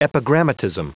Prononciation du mot epigrammatism en anglais (fichier audio)
Prononciation du mot : epigrammatism